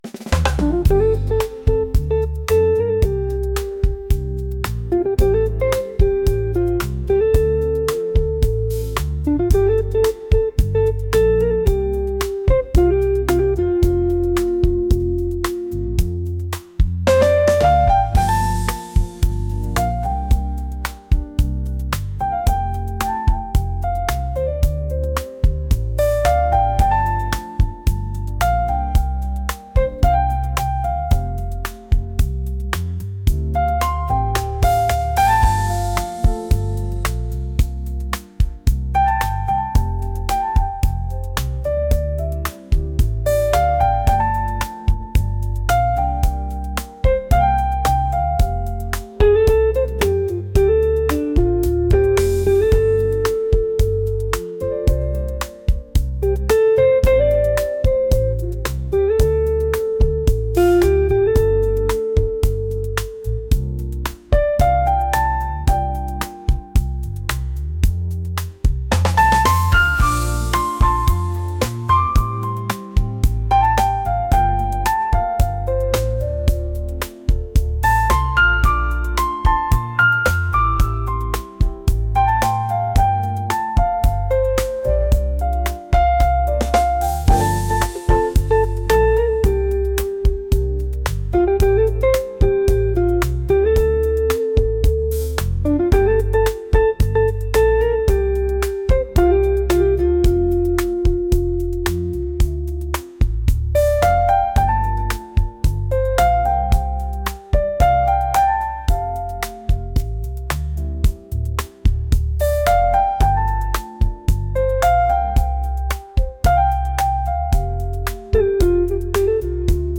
jazz | soulful | smooth